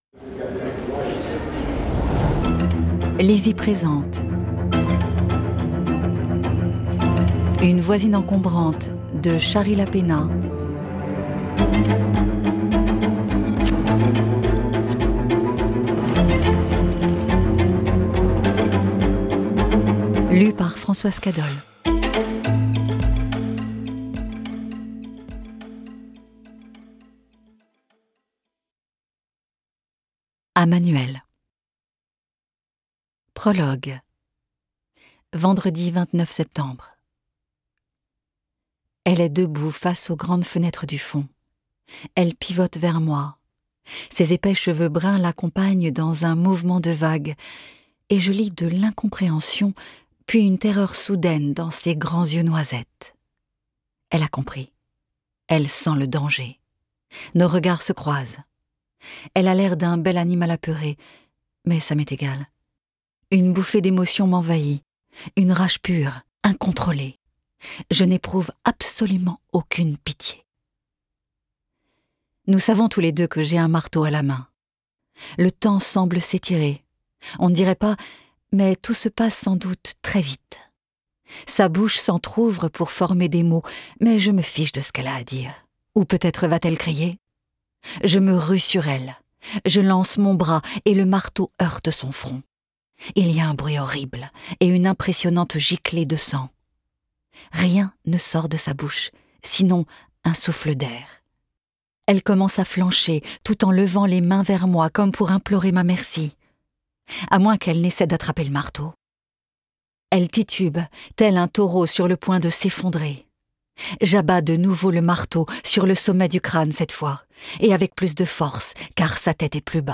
Click for an excerpt - Une voisine encombrante de Shari LAPENA